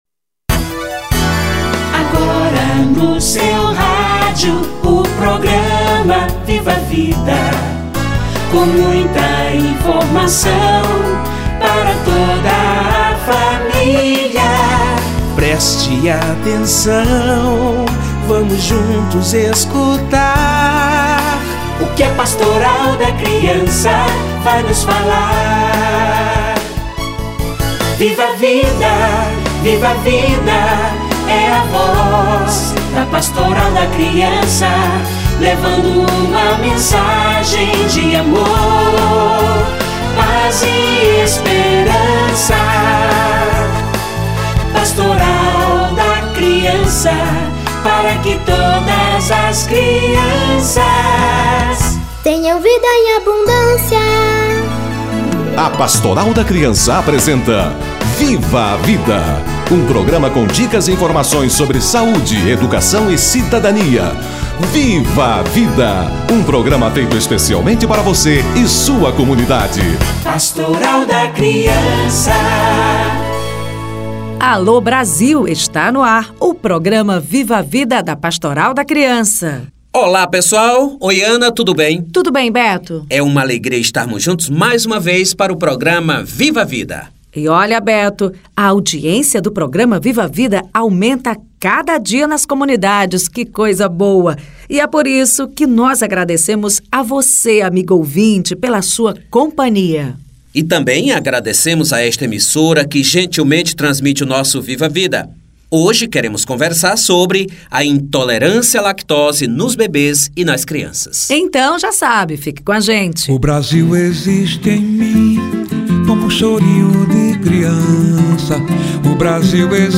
Intolerância à lactose - Entrevista